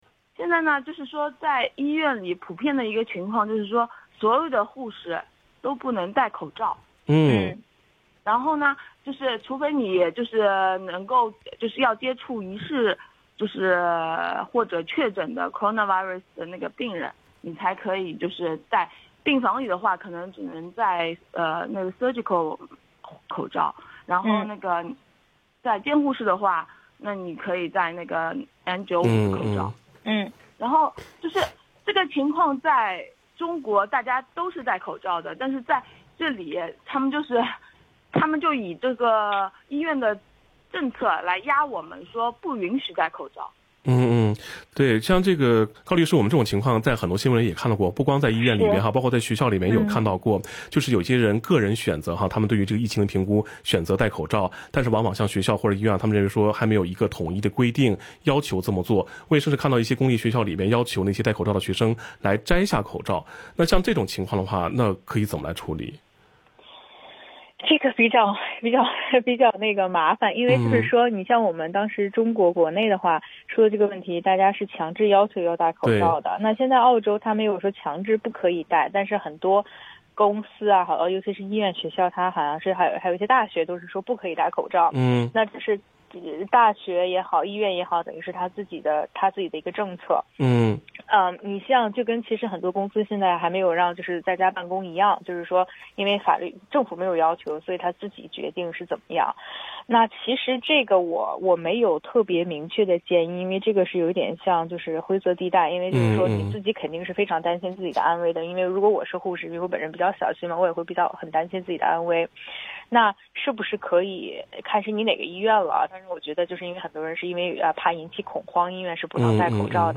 legal_talkback_wearing_mask_or_not.mp3